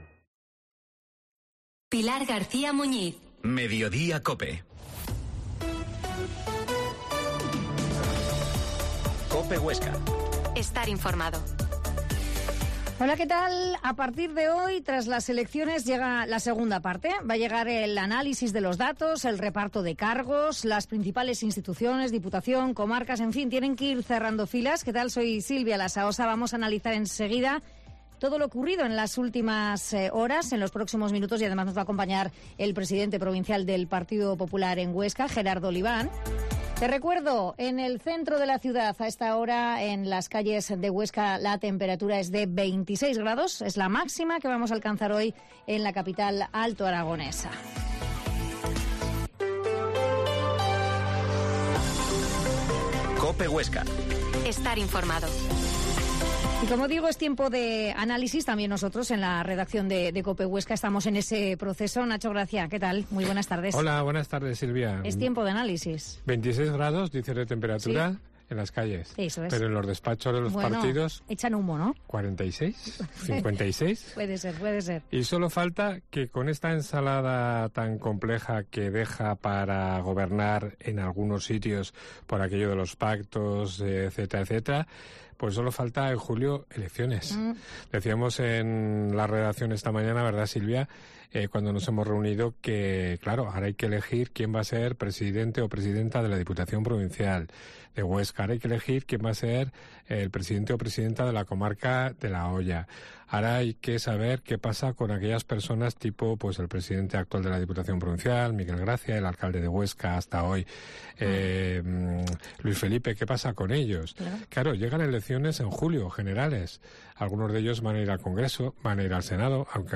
Mediodia en COPE Huesca 13.50h Entrevista al Pte del PP en Huesca, Gerardo Oliván